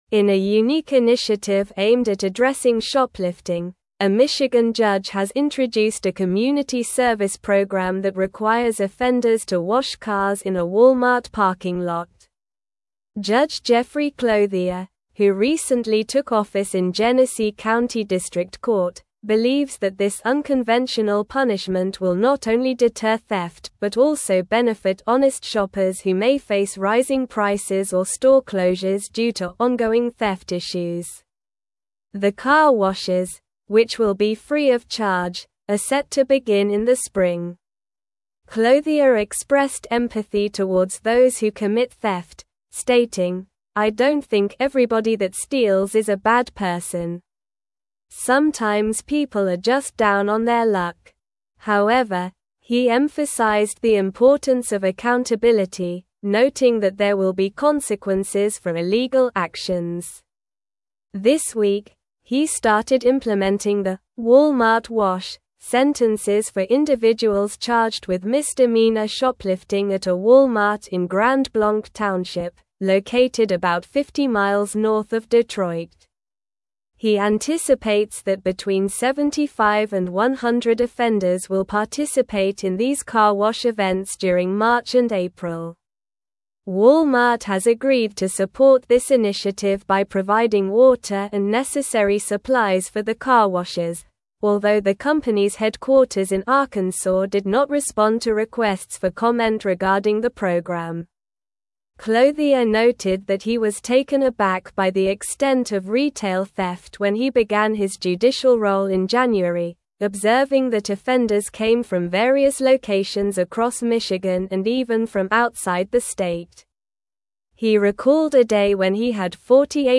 Slow
English-Newsroom-Advanced-SLOW-Reading-Michigan-Judge-Introduces-Unique-Community-Service-for-Shoplifters.mp3